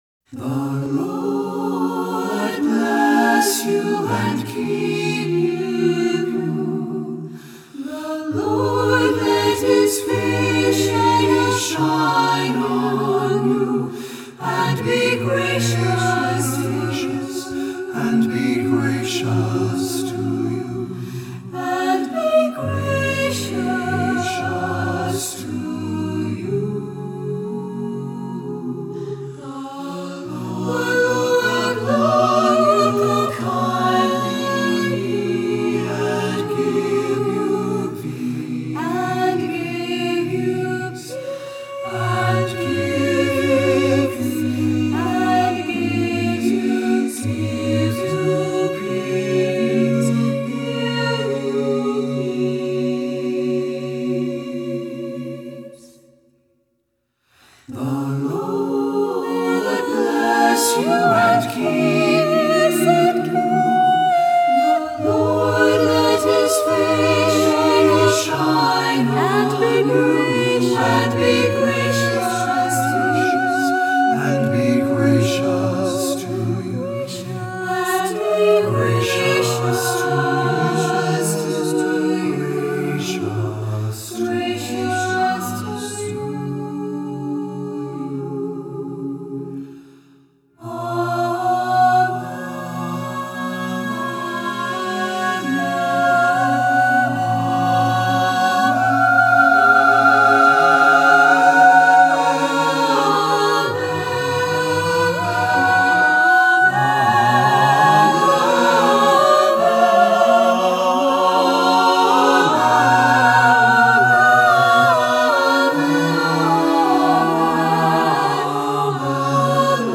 Voicing: a cappella,SATB